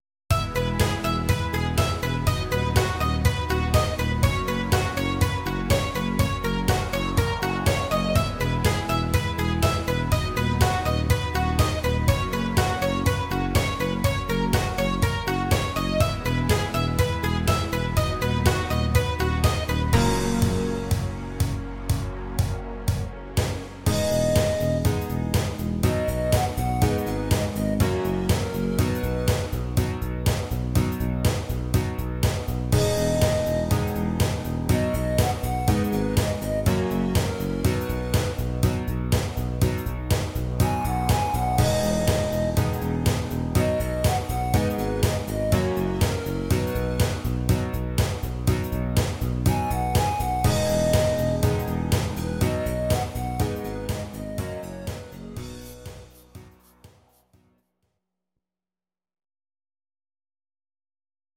Audio Recordings based on Midi-files
Pop, Medleys, 2010s